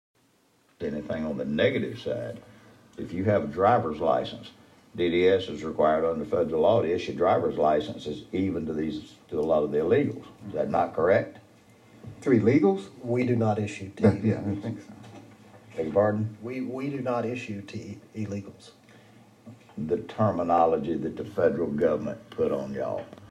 House Motor Vehicles Committee meeting, 2017 session